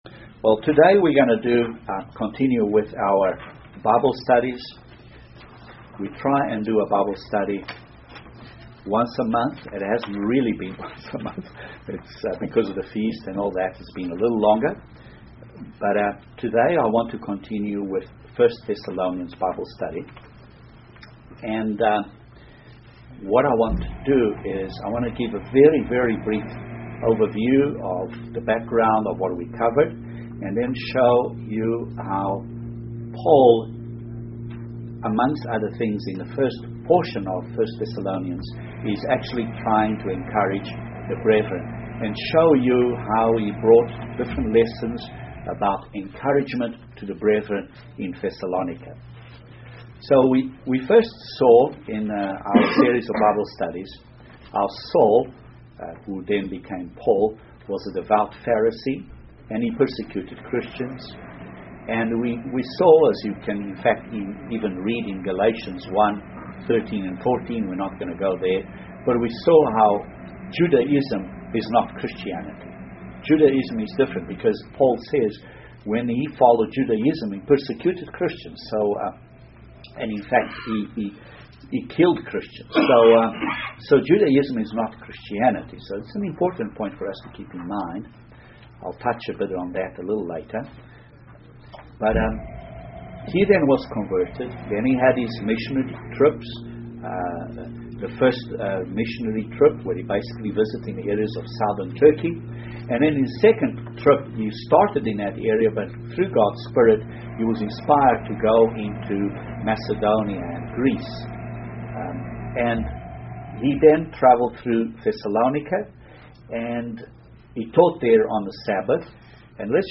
Continuation of Bible Study on 1 Thessalonians. Paul's encouraging coments to the brethren in Thessalonica.